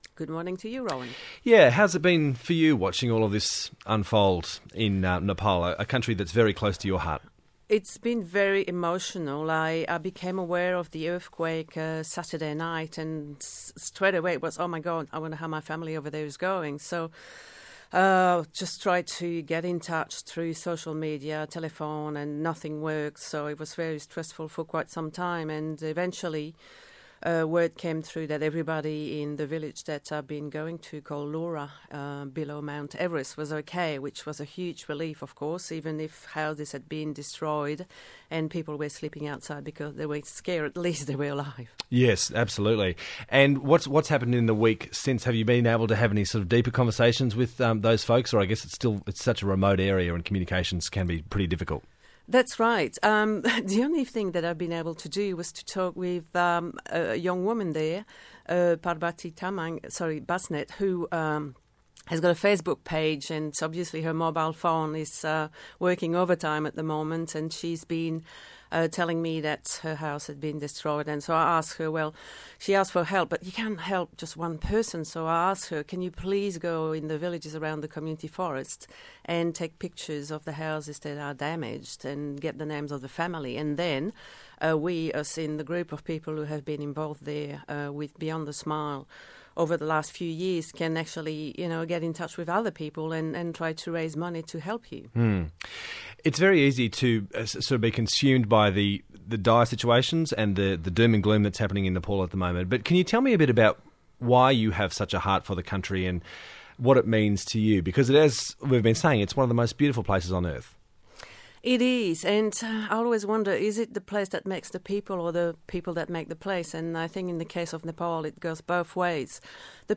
Thank you to the ABC National Radio for letting me share the following interview